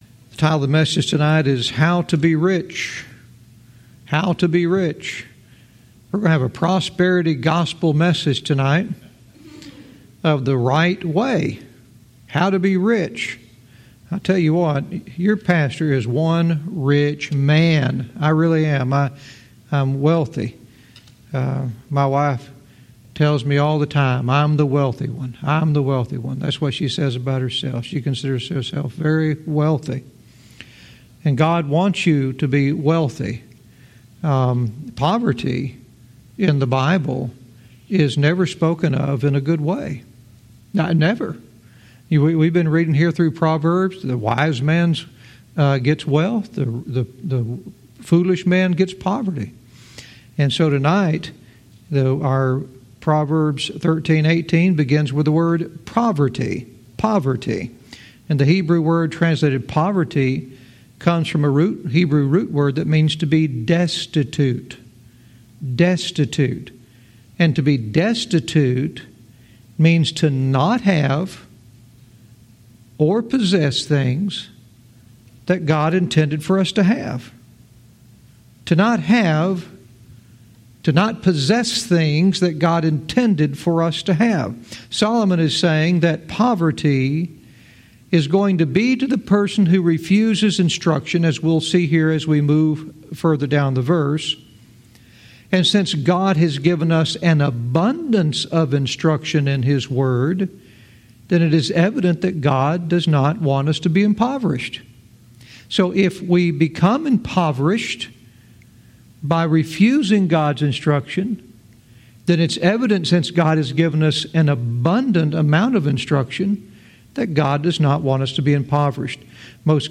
Verse by verse teaching - Proverbs 13:18 "How to Be Rich"